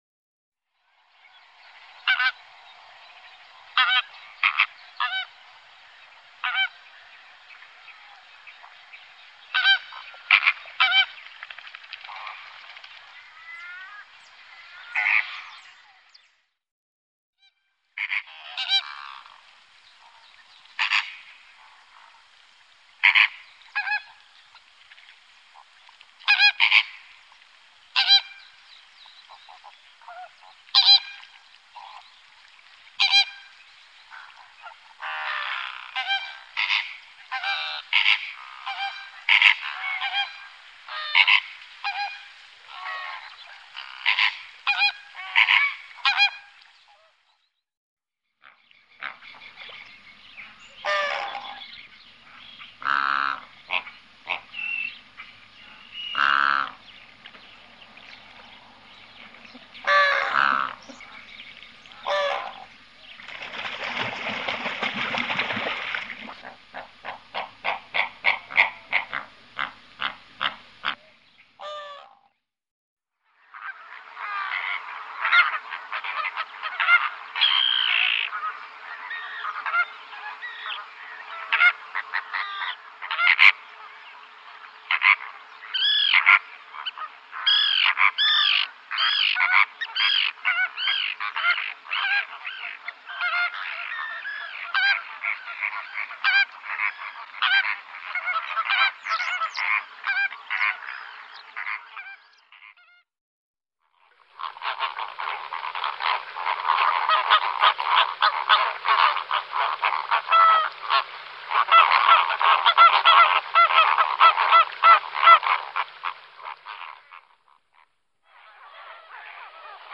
Bilder på Större flamingo / Greater Flamingo
Större flamingo / Greater Flamingo Phoenicopterus roseus Läte / Sound Du är här > Fåglar / Birds > Större flamingo / Greater Flamingo Galleri med utvalda fågelbilder / Favourites Dubai, oktober 2024.
Storre_flamingo.mp3